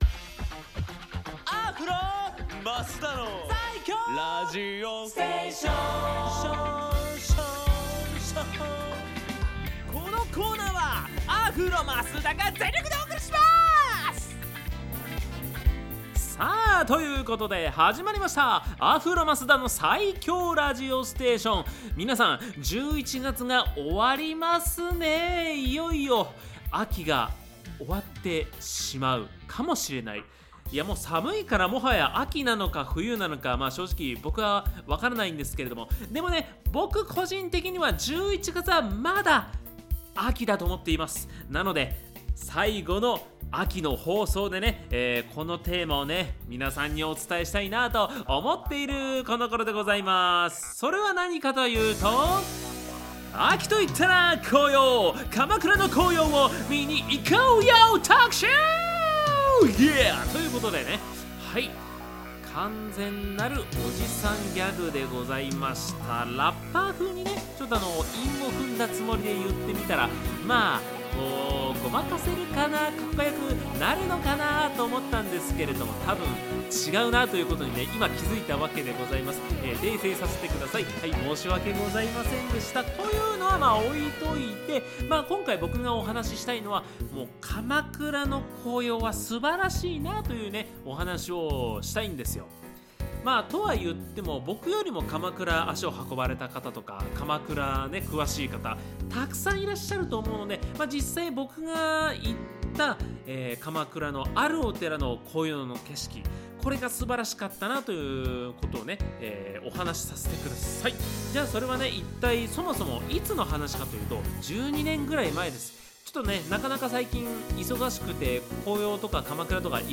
こちらが放送音源です♪